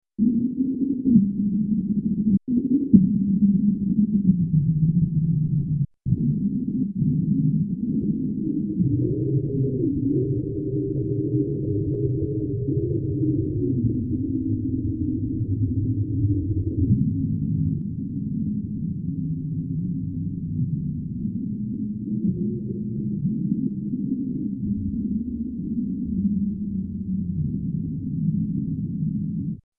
One of his most elaborate inscriptions, published in Phonophotography in Folk Music: American Negro Songs in New Notation (Chapel Hill: University of North Carolina Press, 1929), 82-84, shows the four parts of an African American vocal quartet singing "Swing Low, Sweet Chariot."  Metfessel recorded each of the four parts separately and later plotted them together on a single graph using different colors.
Listen to Metfessel's phonophotographic inscription of "Swing Low, Sweet Chariot" played back in stereo, with the red lines in the left channel:
The playback is set to approximately one bar per second.